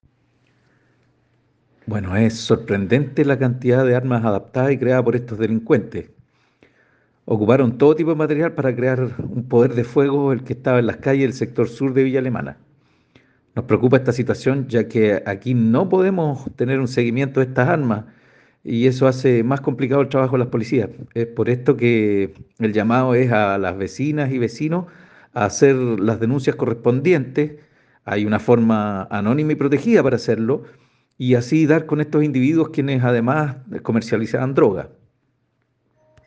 Respecto al operativo, el Delegado Presidencial Provincial, Fidel Cueto Rosales informó que “es sorprendente la cantidad de armas adaptadas y creadas por estos delincuentes”, haciendo el llamado a los vecinos y vecinas a hacer las denuncias anónimas correspondientes: